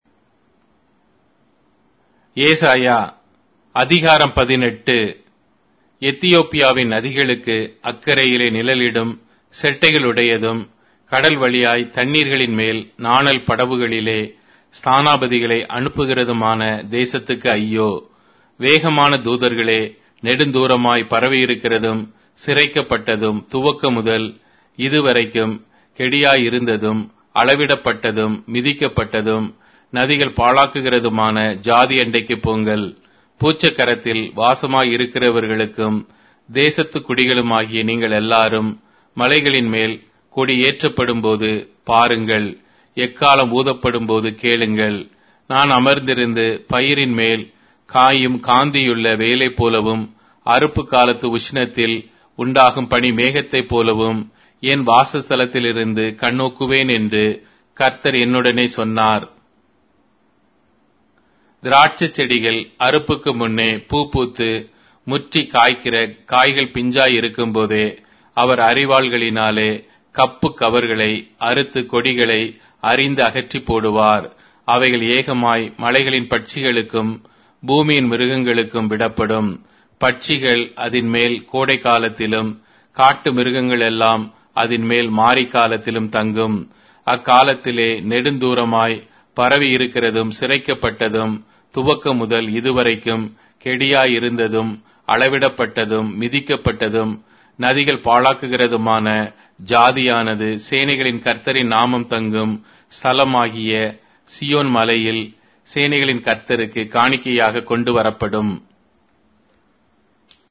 Tamil Audio Bible - Isaiah 31 in Gnttrp bible version